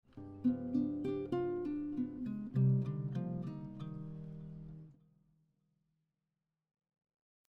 Harmonic minor scale lick 2